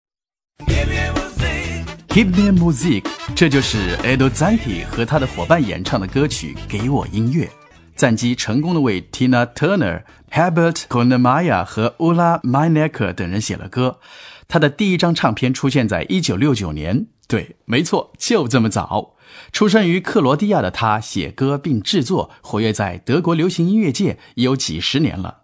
Sprecher chinesisch, Übersetzer, Regisseur,
Sprechprobe: Industrie (Muttersprache):
chinese male voice over talent